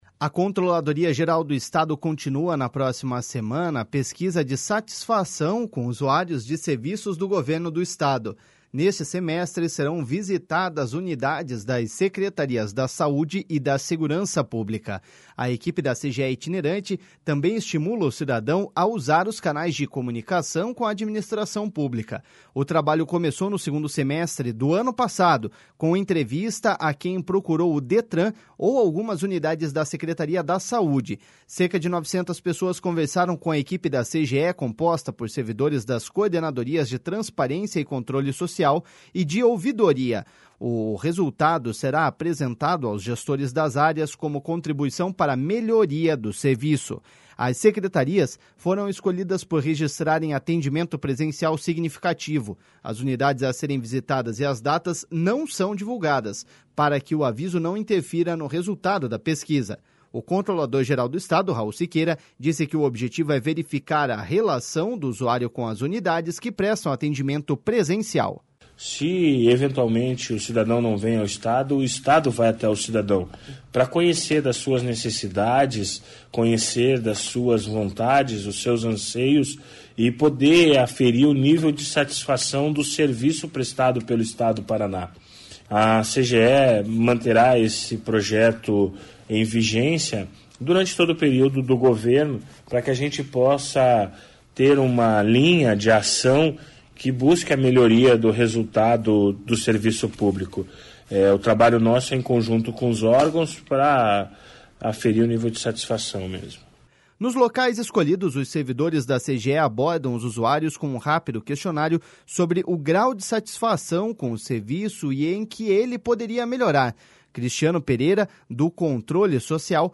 O controlador-geral do Estado, Raul Siqueira, disse que o objetivo é verificar a relação do usuário com as unidades que prestam atendimento presencial.// SONORA RAUL SIQUEIRA.//